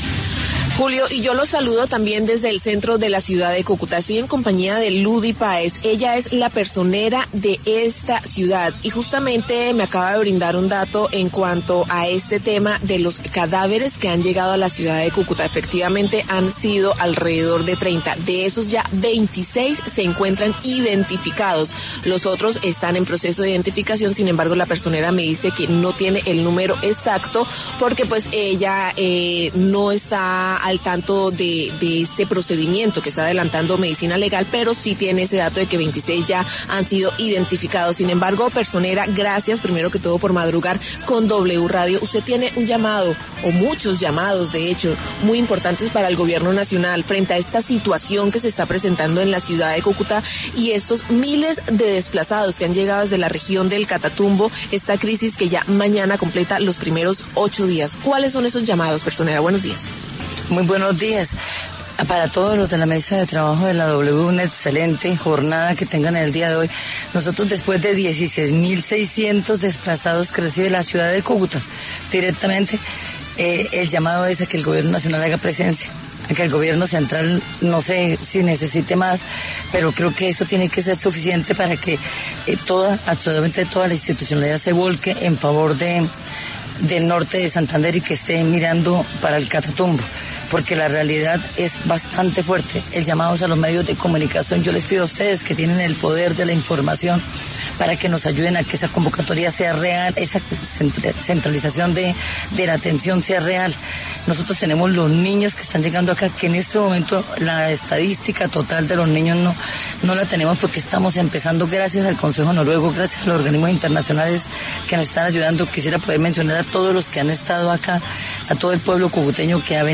Ludy Páez, personera de Cúcuta, pasó por los micrófonos de La W y entregó detalles de la crisis que se vive en el Catatumbo por cuenta de los enfrentamientos entre el ELN y disidencias Farc.